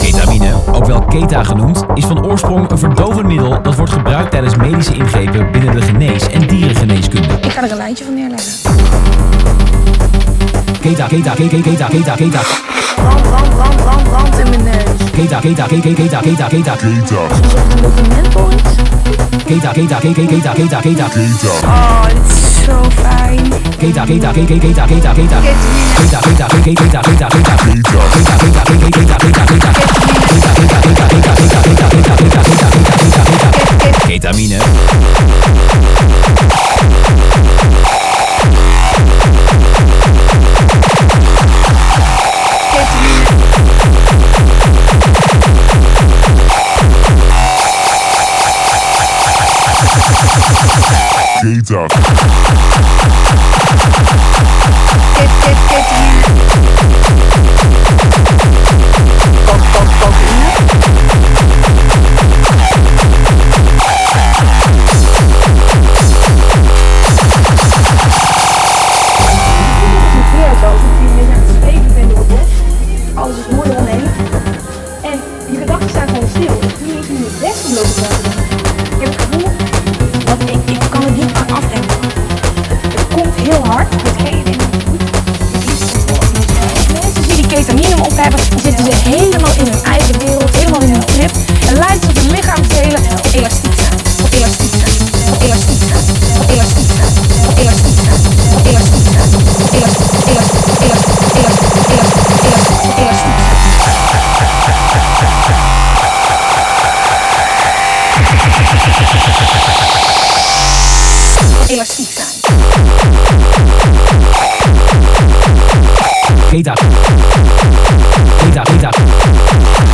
uptempo hardcore # 220bpm